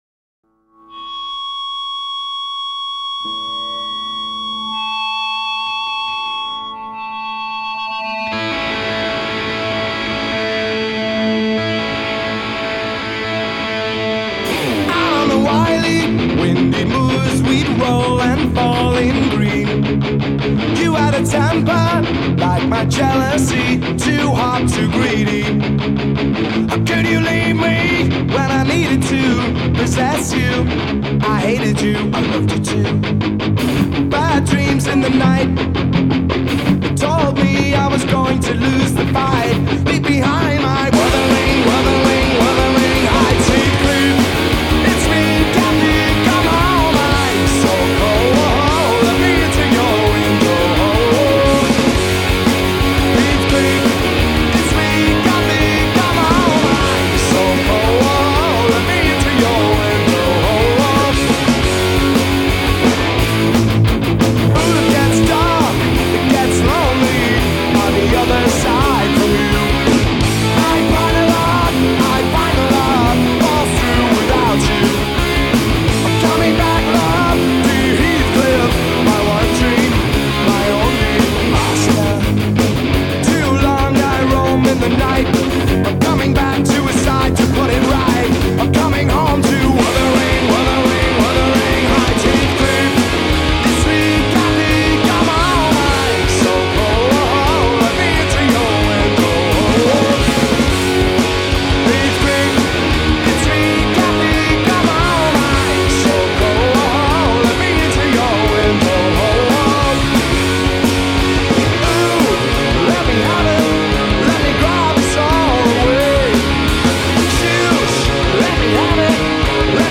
British punk band